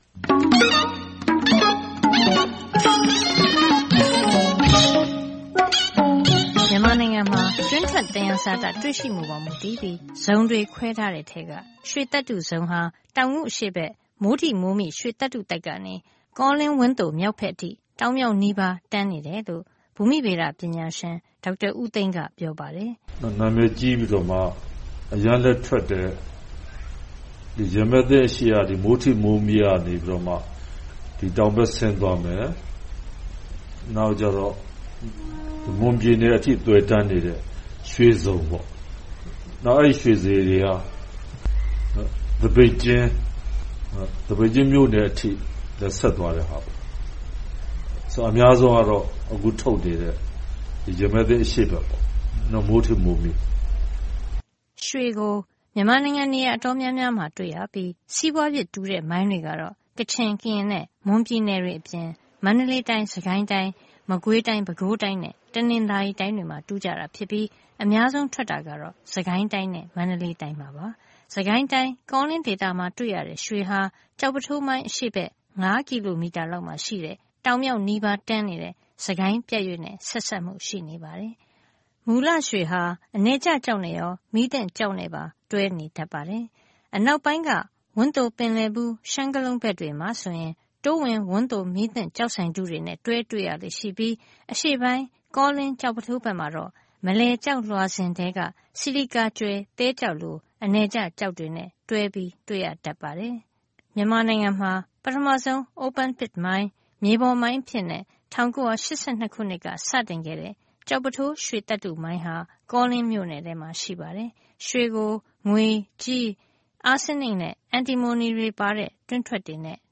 ဖေဖေါ်ဝါရီ ၁၈၊ ၂၀၁၈ - မန်မာနိုင်ငံ ရွှေတူးဖော်ရေး အခြေအနေ၊ လုပ်ငန်းဆိုင်ရာ၊ ပတ်ဝန်းကျင်ထိခိုက်မှု အနေအထားများကို သိရှိရအောင် သက်ဆိုင်သူများကို တွေ့ဆုံမေးမြန်းထားပါတယ်။